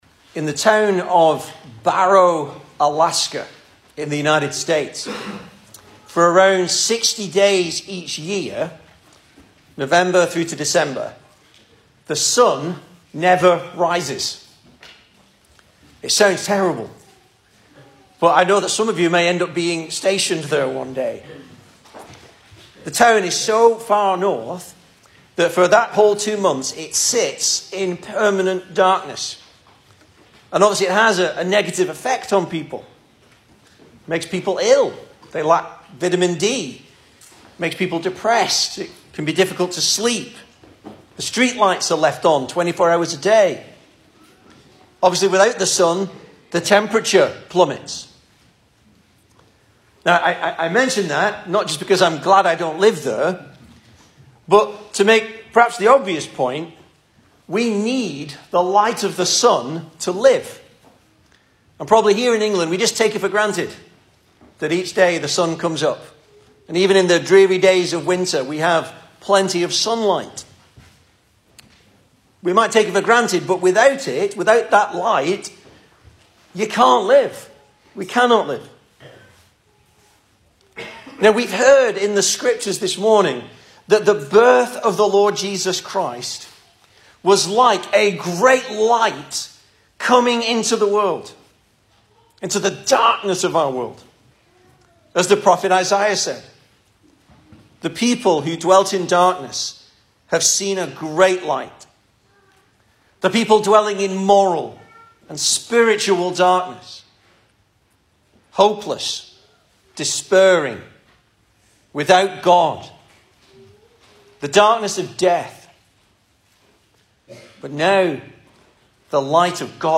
Service Type: Sunday Morning
Series: Christmas Sermons